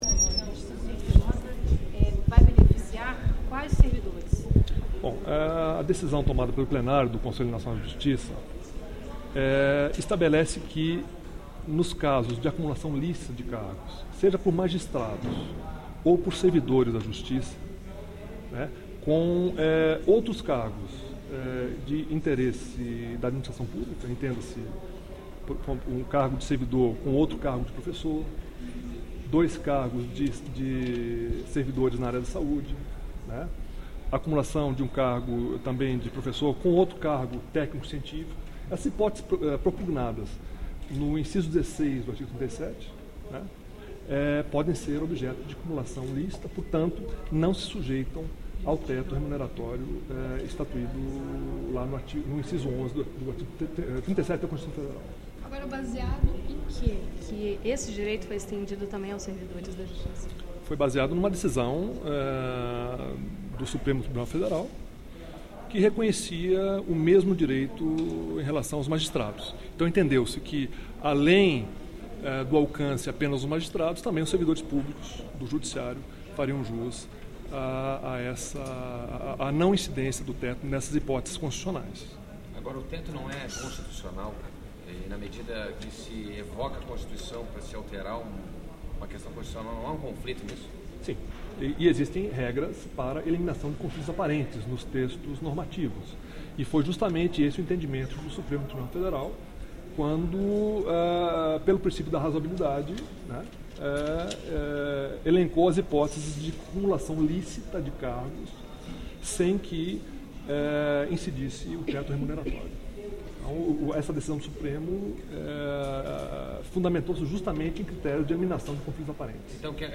alvaro_ciarlini_teto.mp3